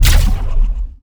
WULA_RW_Railgun_Shootingsound.wav